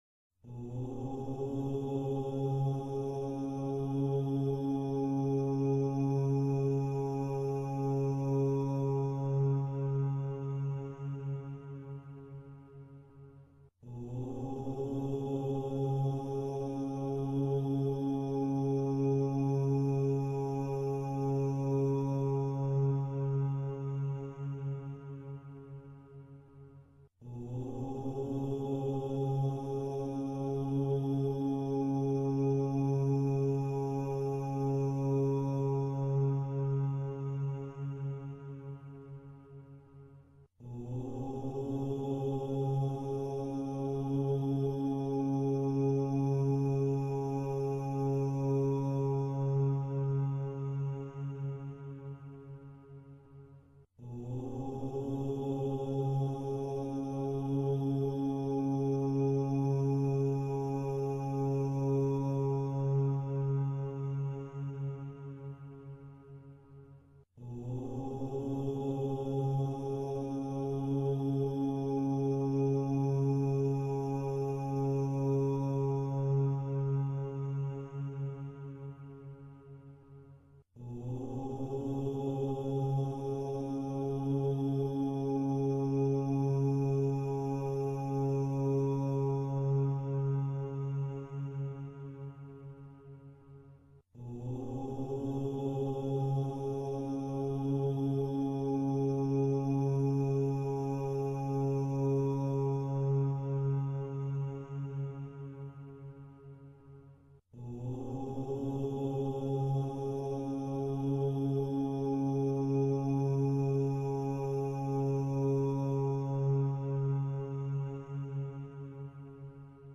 Om-chanting-new.mp3